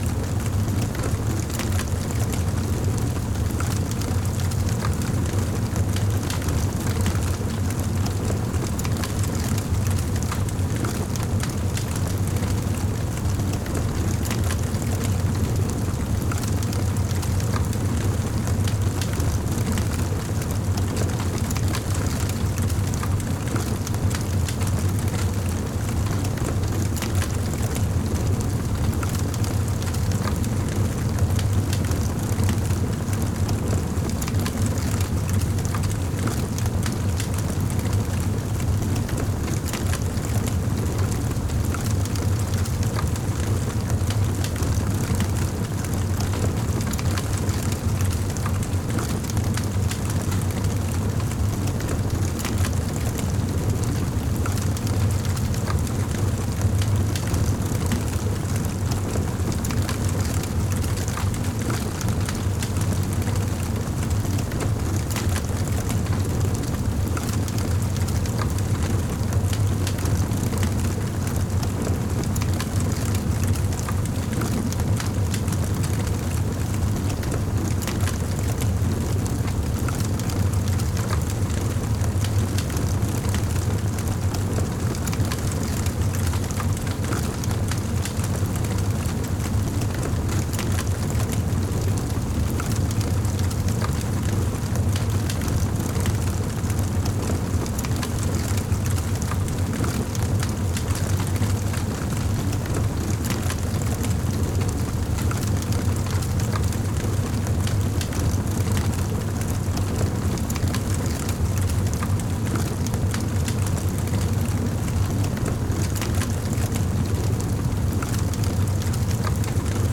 large_fire.mp3